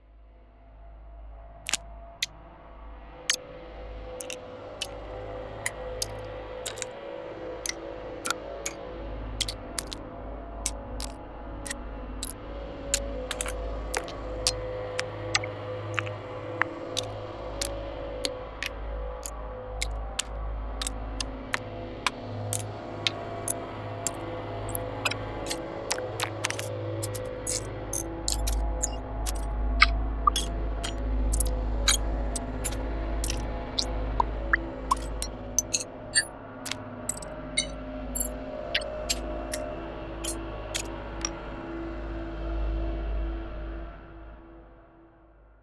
80種類のミニマルかつ高解像度なクリック音を収録したこの洗練されたコレクションは、スムーズなユーザーインタラクションのために設計されています。
スマートなモバイルメニュー、インタラクティブなインスタレーション、未来的なHUDまで、緻密に作り込まれたタップ、ティック、トリガーが完璧なサウンドを提供します。
音源は、繊細でやわらかな反応音を収めた「SoftClicks」と、よりシャープで触感的なアクセント音を収めた「SnappyClicks」の2つのフォルダーに分類されており、インターフェースのフィードバックに必要な音色の幅を網羅しています。
どの音もクリーンでモダン、アプリやゲーム環境、UI/UXにそのままスムーズに使用可能です。
デモサウンドはコチラ↓
Genre:Cinematic